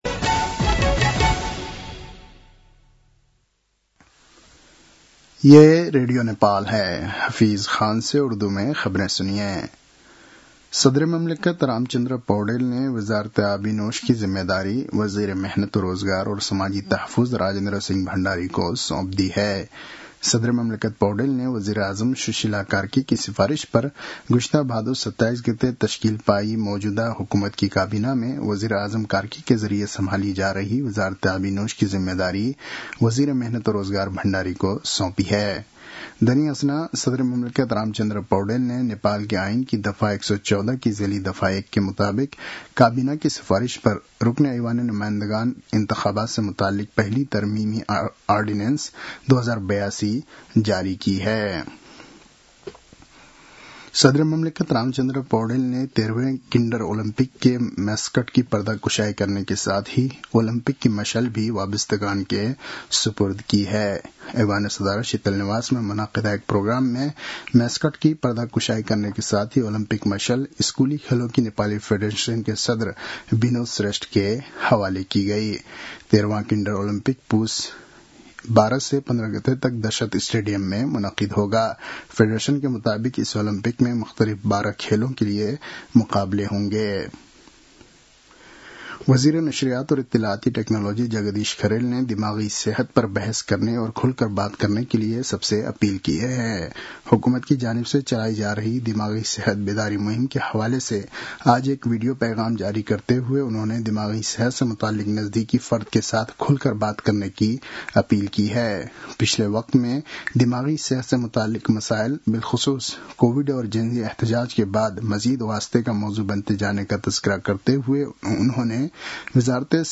उर्दु भाषामा समाचार : ४ पुष , २०८२
Urdu-news-9-04.mp3